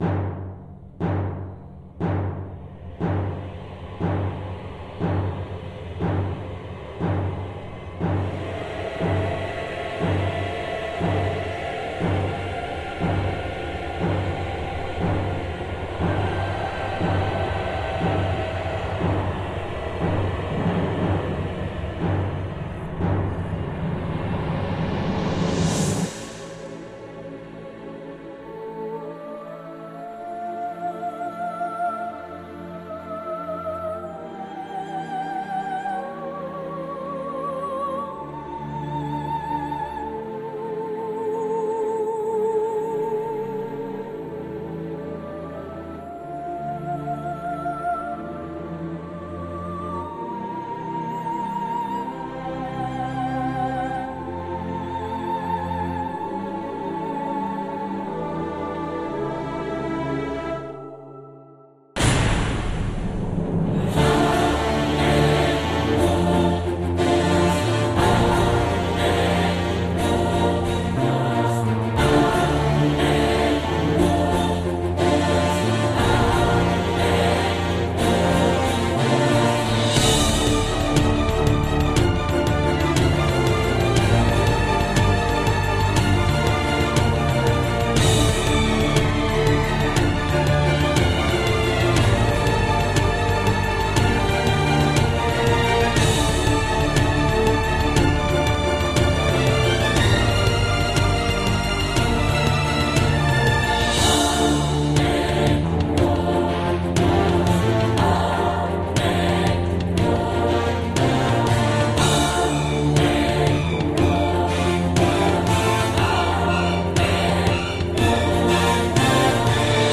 Notas de prensa para un - Drama Piro-Musical de fuego -: